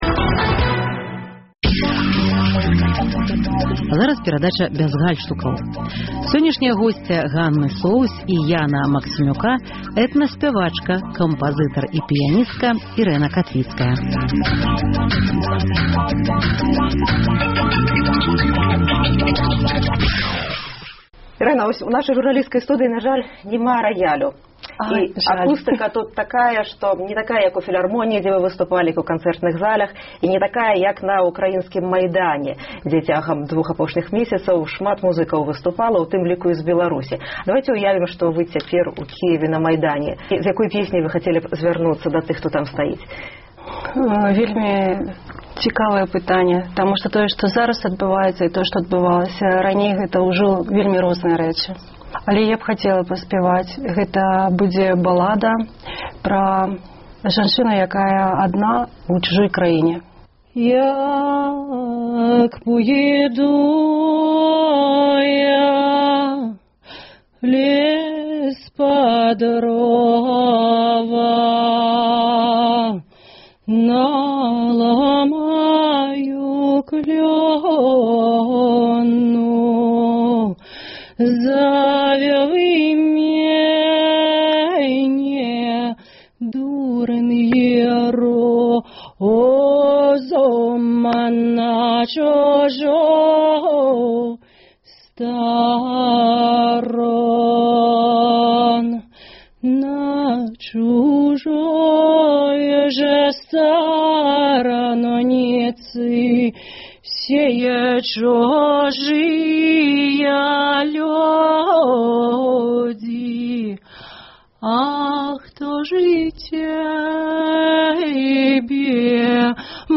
праскай студыі «Бяз гальштукаў»